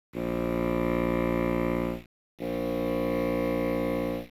The were calculated assuming the listener and the bassoon were in a room measuring 10m x 10m x 25m.
In each case, the reverberant sound was mixed from six reflections.
The reverberant signals from the bassoon, 5m away, then 15m away